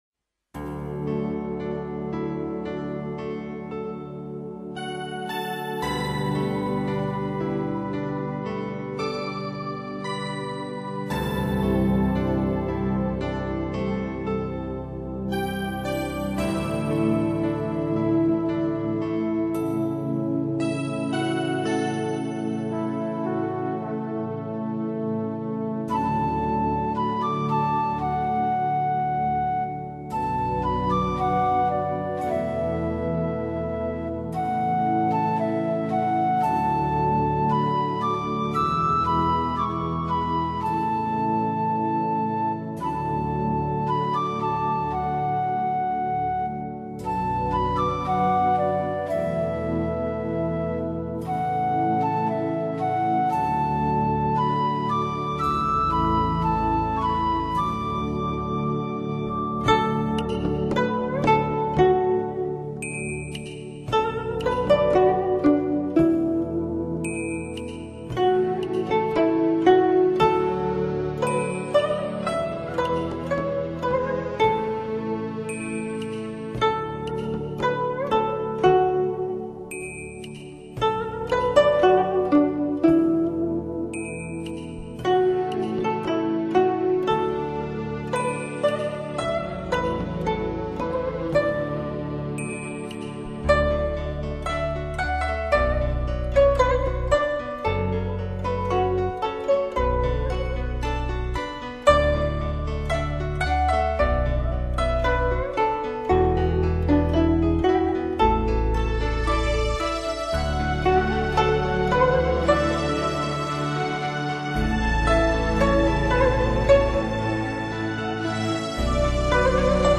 ☆超塵脫俗的民間樂曲演奏專輯；改變傳統音樂既有形象、賦予民樂全新風貌，是寫景寫意的傑出作品。